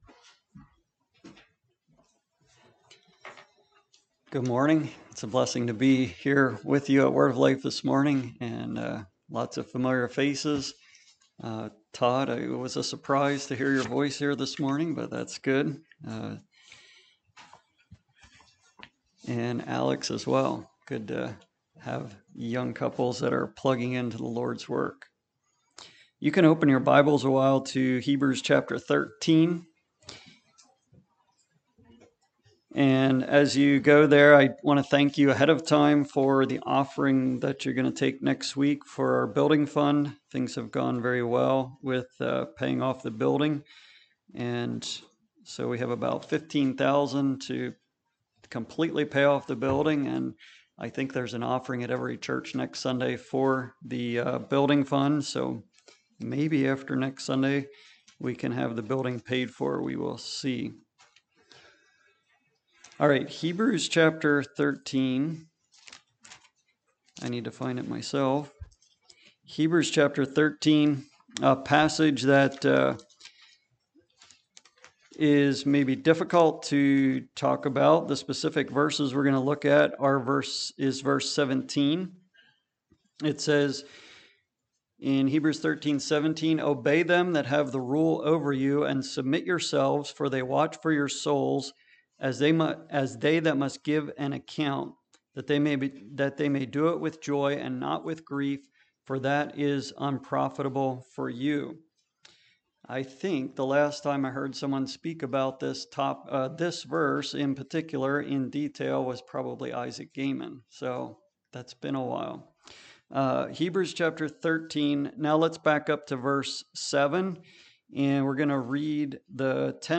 Listen to sermon recordings from Word of Life Mennonite Fellowship.